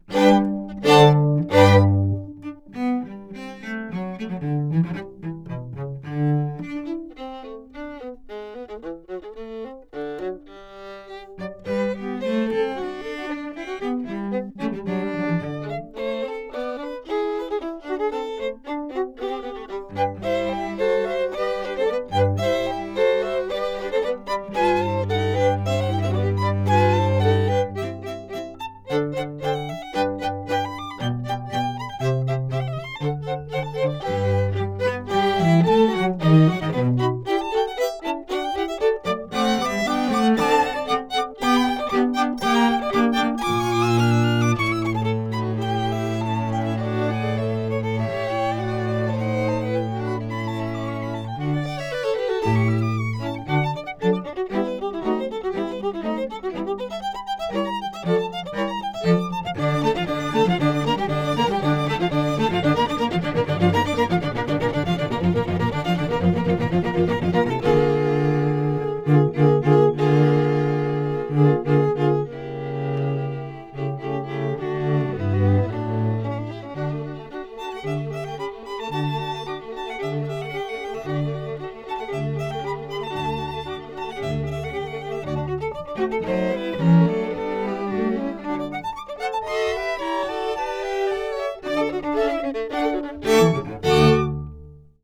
Quartet_Anechoic.wav